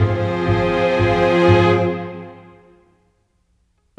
Rock-Pop 11 Strings 08.wav